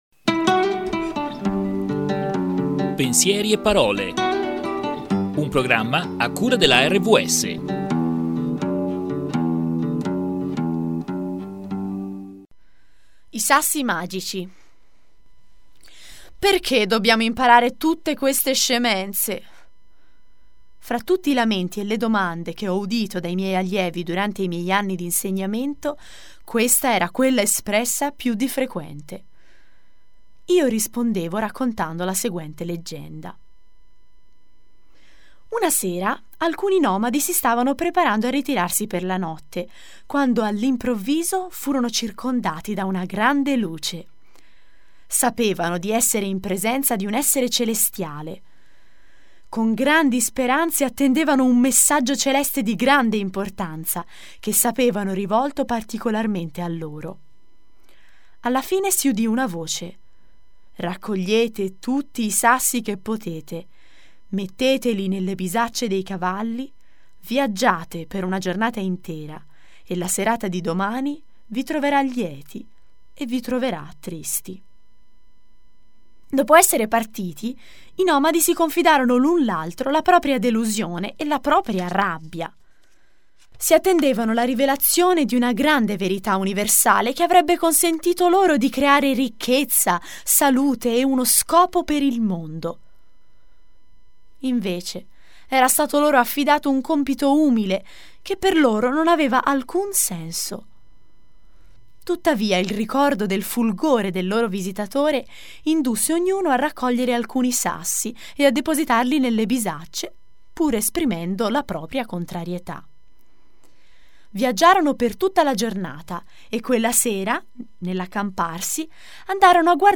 Radio Podcast